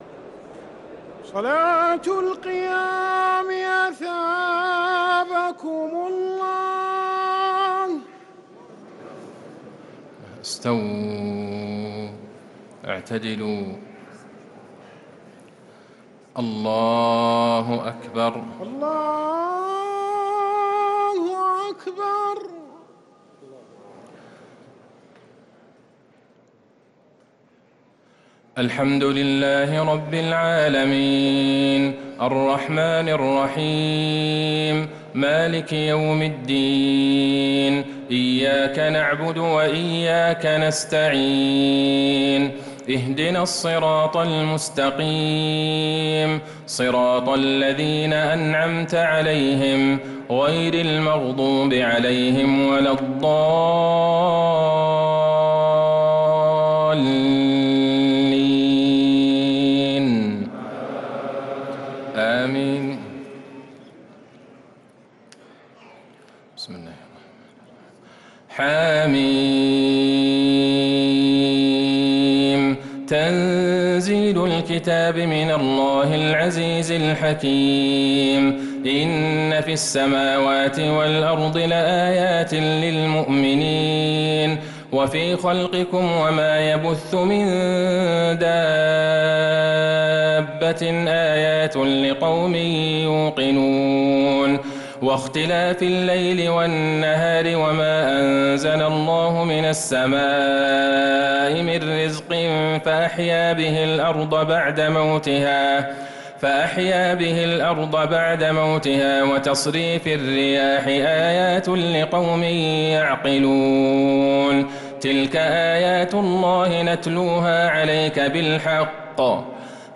تهجد ليلة 26 رمضان 1446هـ من سورة الجاثية الى محمد كاملة | Tahajjud 26th night Ramadan 1446H Surah Al-AlJathiyah to Muhammad > تراويح الحرم النبوي عام 1446 🕌 > التراويح - تلاوات الحرمين